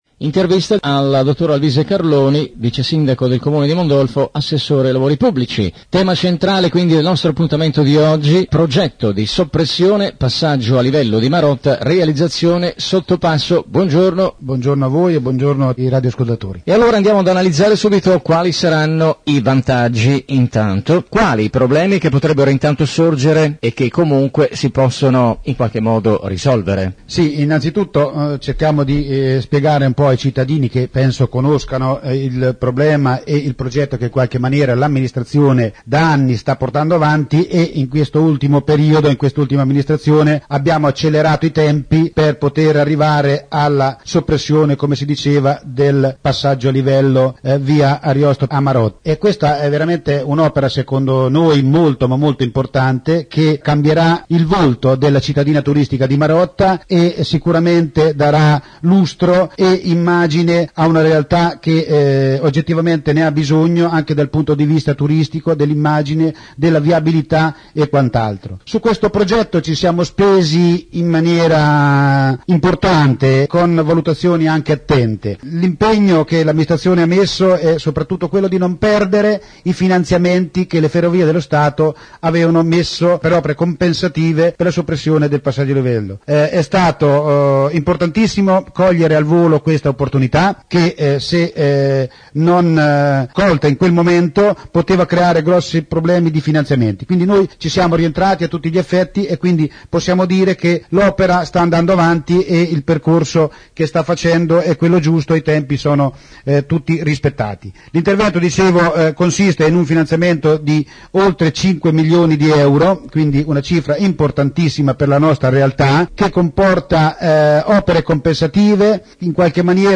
New Radio Star | Intervista Vice Sindaco e ass. Lavori Pubblici in riferimento alla soppressione passaggio a livello della linea ferroviaria a Marotta nel comune di Mondolfo
intervista-Vice-Sindaco-comune-di-Mondolfo-Alvice-Carloni.mp3